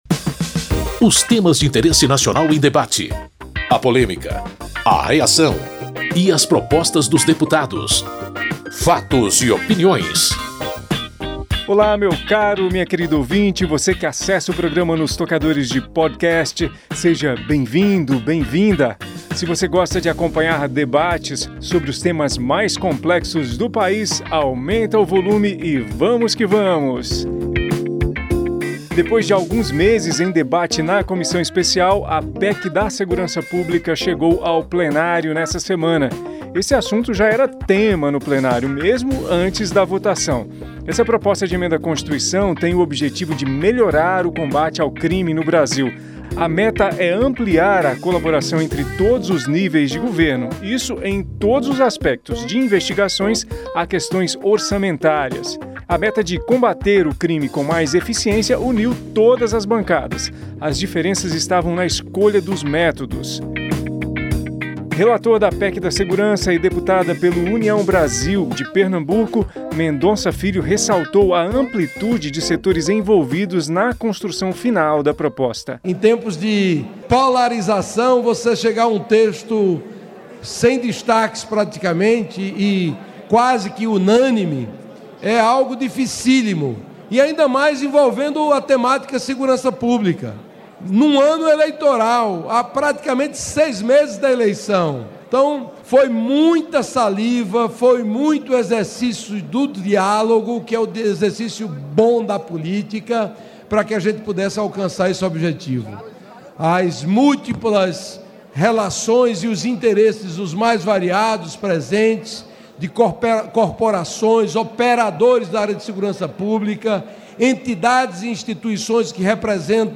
Com linguagem atraente, o programa apresenta uma coletânea dos discursos feitos em Plenário ao longo da semana, editados por tema.